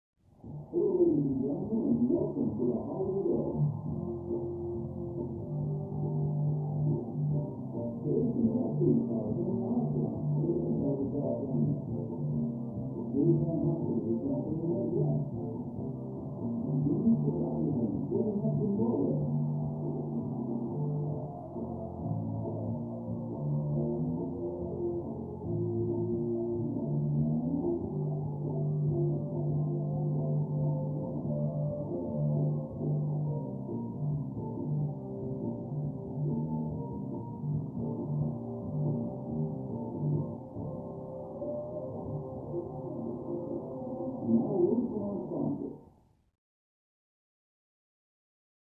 Television; Game Show Opening With Music, Announcer And Crowd. Through Thick Wall.